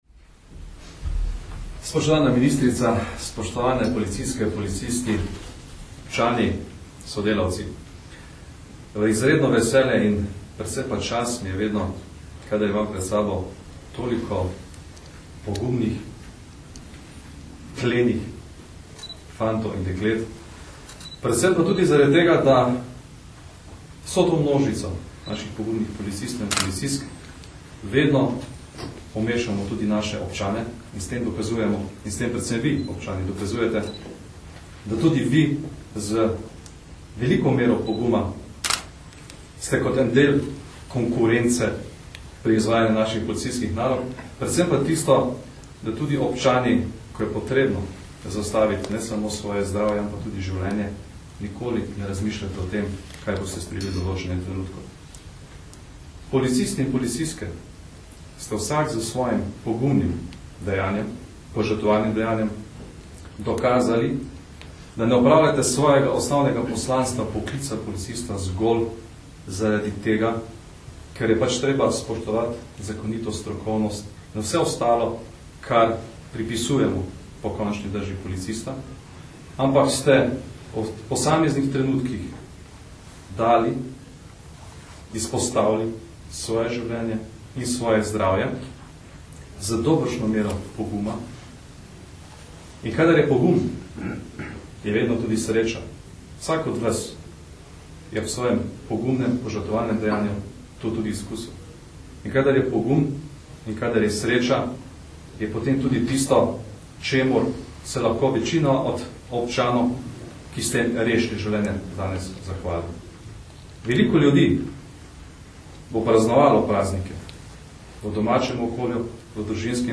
Generalni direktor policije Janko Goršek in ministrica za notranje zadeve Katarina Kresal sta danes, 8. decembra 2010, v Policijski akademiji v Tacnu podelila 22 medalj policije za požrtvovalnost in 9 medalj policije za hrabrost.
Zbrane je nagovoril generalni direktor policije Janko Goršek, ki se je vsem zahvalil za izjemen pogum in nesebičnost ali pa več kot strokovno opravljanje svojega dela ter jim čestital za prejeta priznanja.
Zvočni posnetek nagovora generalnega direktorja policije Janka Gorška (mp3)